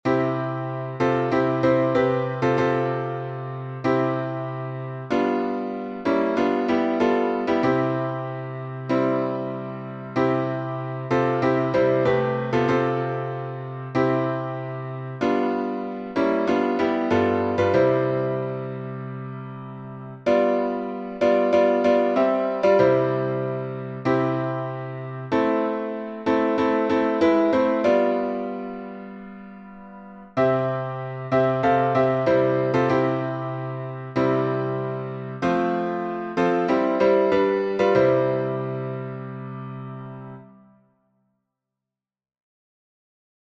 Key signature: C major (no sharps or flats) Time signature: 4/4 Meter: 8.7.8.7. with Refrain Public Domain 1.